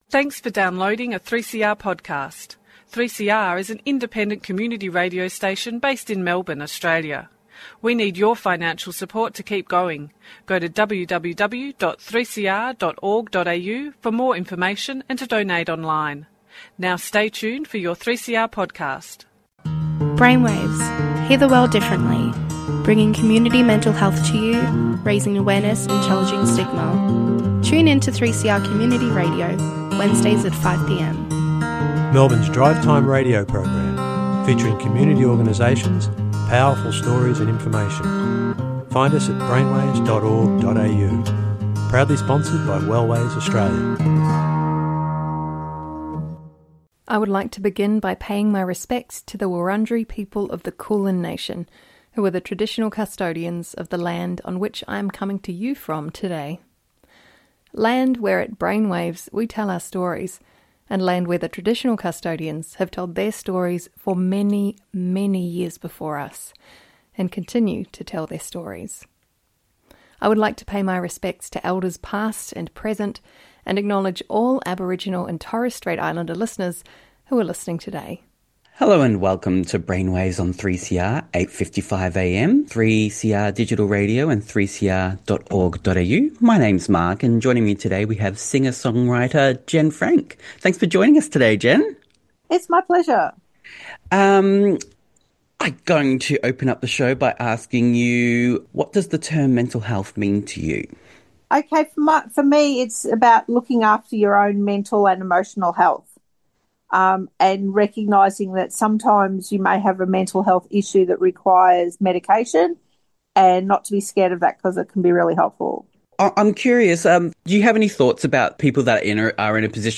Presenter Members of Wellways Australia